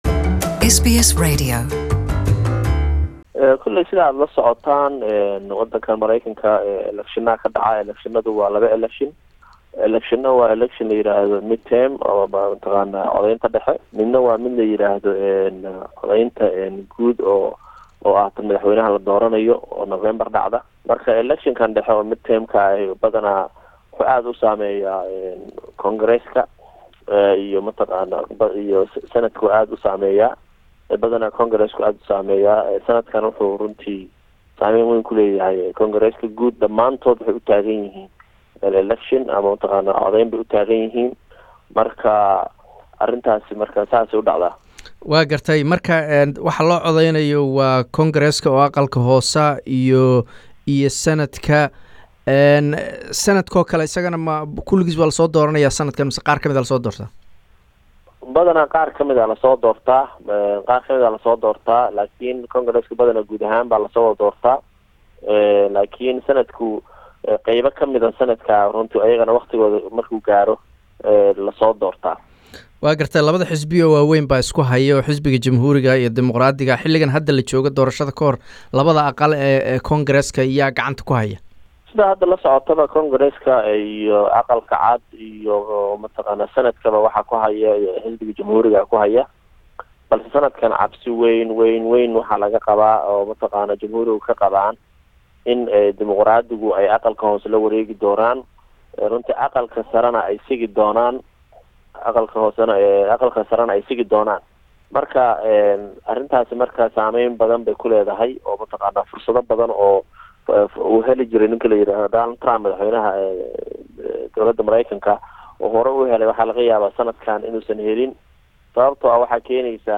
Interview
Waraysi